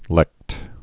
(lĕkt)